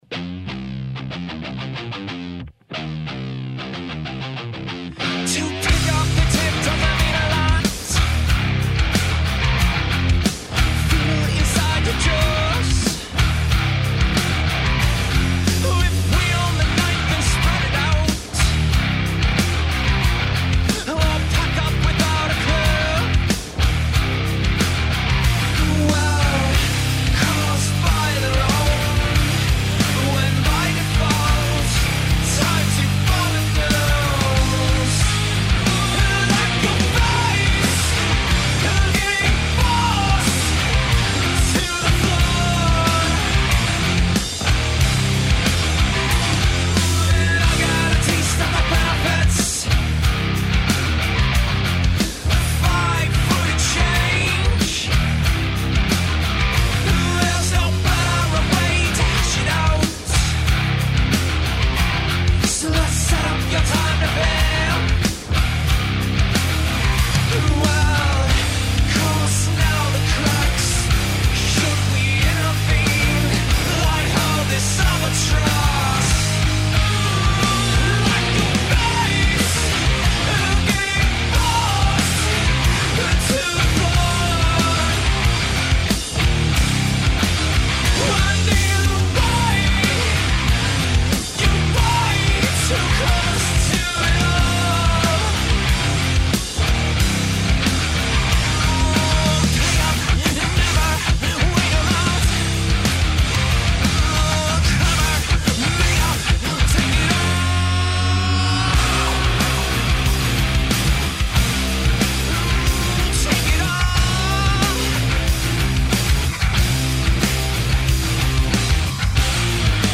Easy on the head.